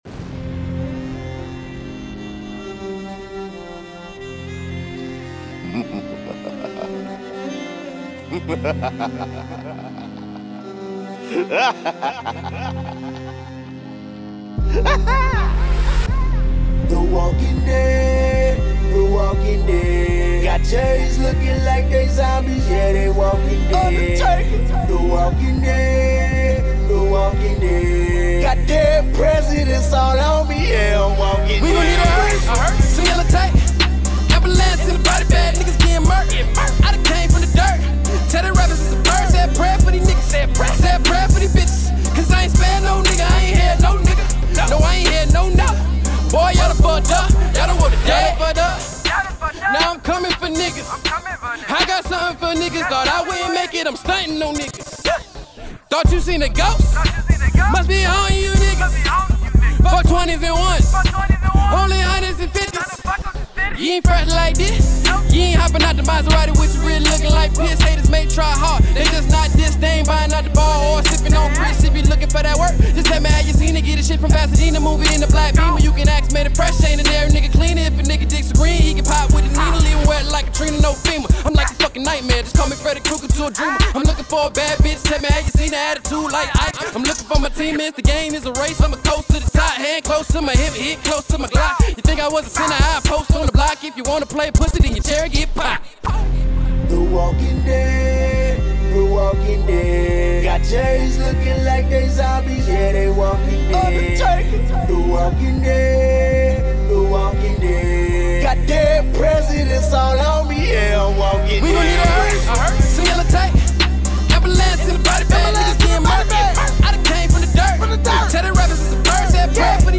Hiphop
Halloween themed street single with an infectious sound
unforgettably dark hymn
genre merging wordplay
country rapper